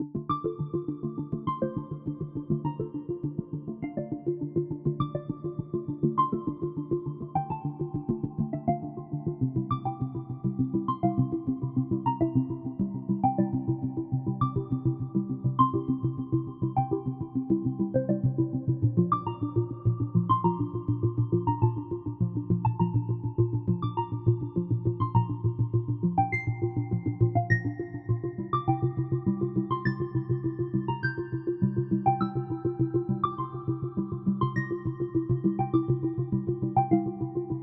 铃铛旋律复古合成器浪潮21阿贝鲁斯
Tag: 102 bpm Ambient Loops Bells Loops 6.33 MB wav Key : Unknown FL Studio